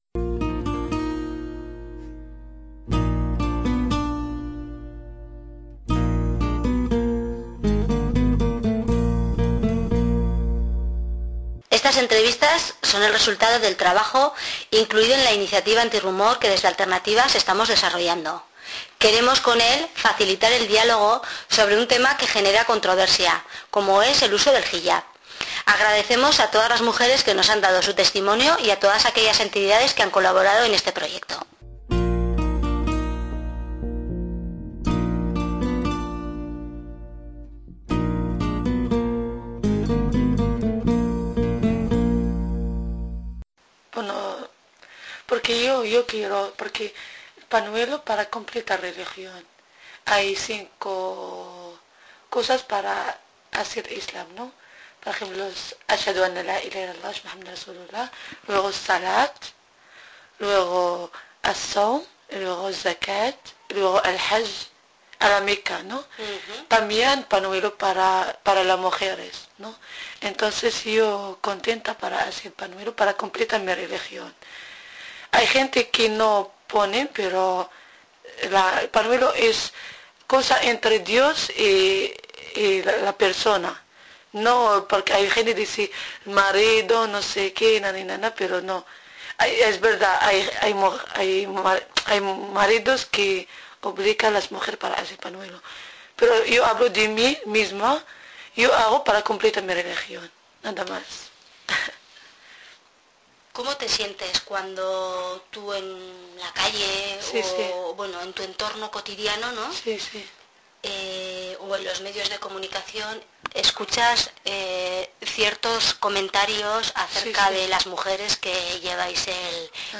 ENTREVISTA Debajo del Velo: Rumores y Creencias.